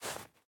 Minecraft Version Minecraft Version snapshot Latest Release | Latest Snapshot snapshot / assets / minecraft / sounds / block / powder_snow / break5.ogg Compare With Compare With Latest Release | Latest Snapshot